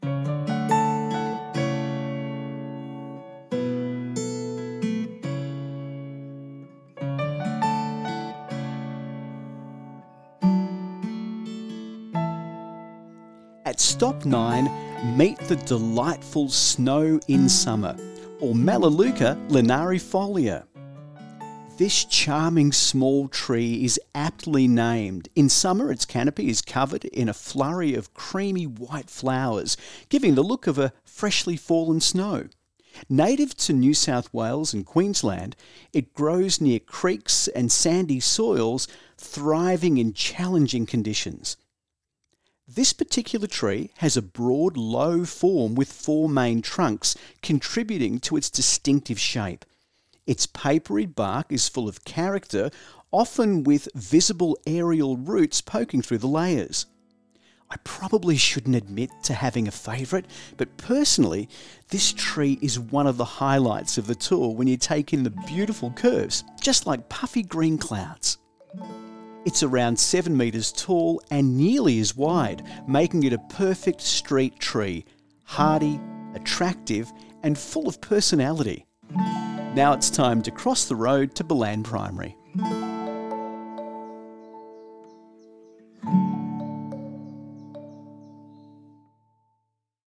Audio Tour of the Ballan Historic Tree Walk